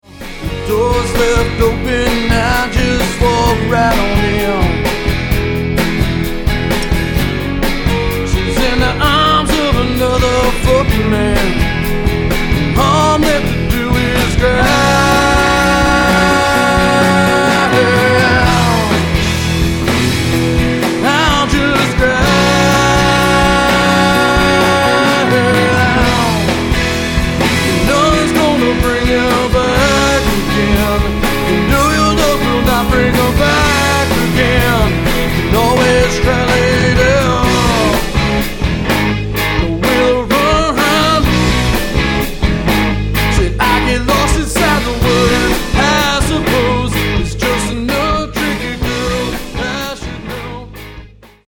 The tracks were recorded primarily "live"
stripped down and hard-rocking.